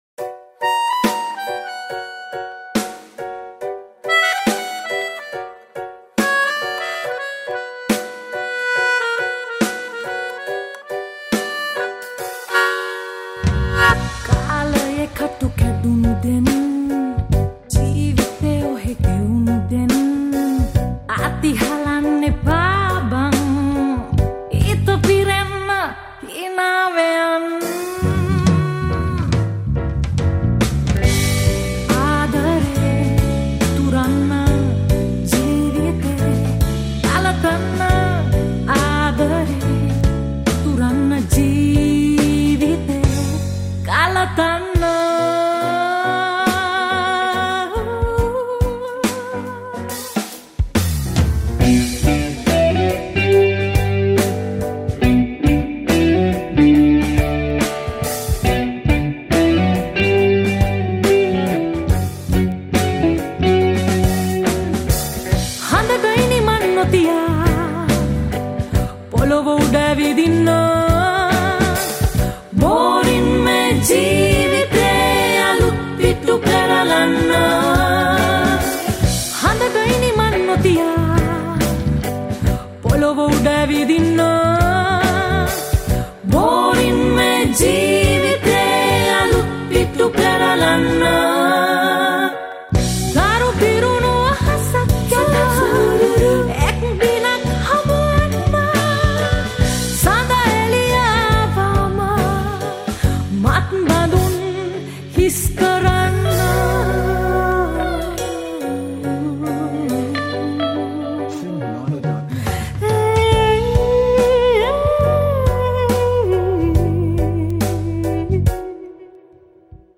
Teledrama Song